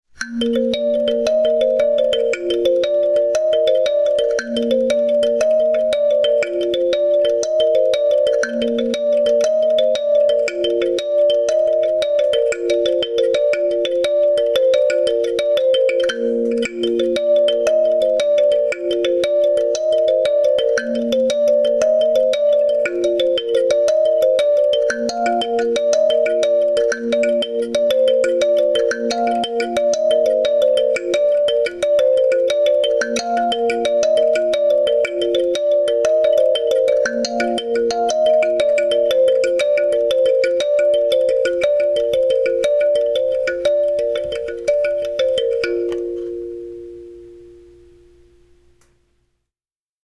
The repeated cycles tend to be about 5 or 10 seconds long, and can have as few as 4 or 5 notes, and as many as 12 or 16 or 24 notes or more.
Here is an example of my cyclic kalimba music – though as you can hear, my cycles shift around a bit:
I myself find that this sort of repetitive kalimba improvisation reduces anxiety and comforts me.
Stimming_Cycles.mp3